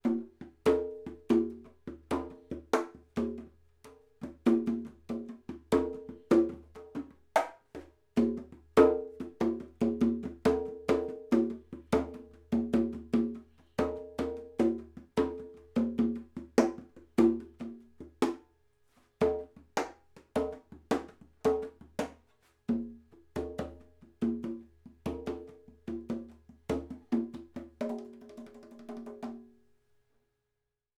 Spaced cardiods
2 x 1/2″ Cardiods, spaced 1,5 m.
Spaced, Cardioid Congas
Spaced_Cardioid_Congas.wav